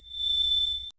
soft-bell-tone-with-cryst-3zd5cz77.wav